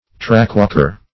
Search Result for " trackwalker" : The Collaborative International Dictionary of English v.0.48: Trackwalker \Track"walk`er\, n. (Railroads) A person employed to walk over and inspect a section of tracks.